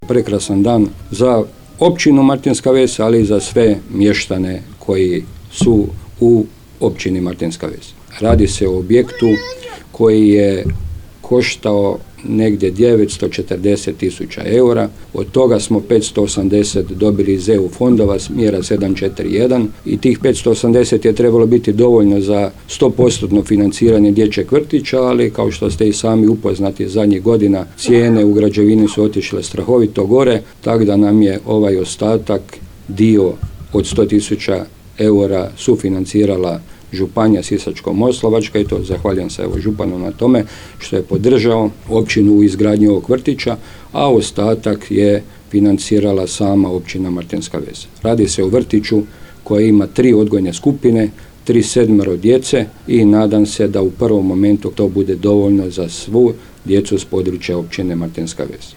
Načelnik Stjepan Ivoš ističe kako je Dječji vrtić „Martinska Ves“ u naselju Strelečko prvi vrtić na području ove općine